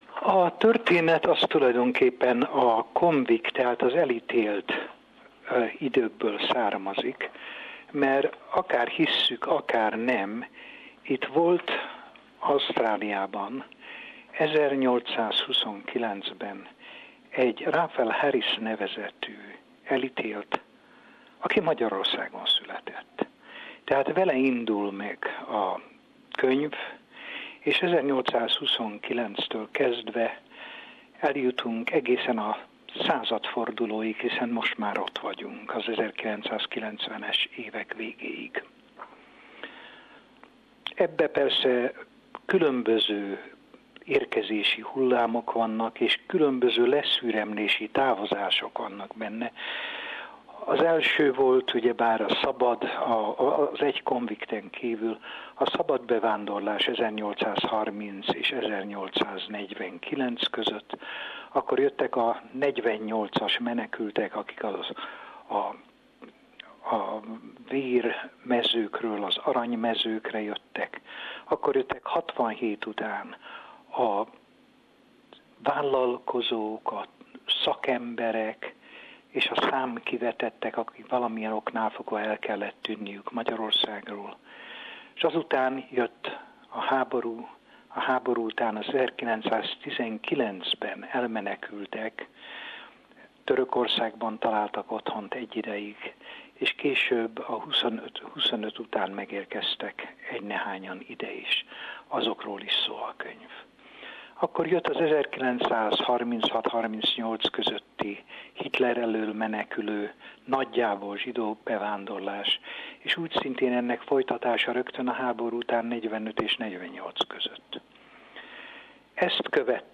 Elméletét a kivándorló és a menekült közötti különbségről, valamint menekült típusokról nemzetközileg elismerik. Az SBS Rádió számára 1997-ben adott interjút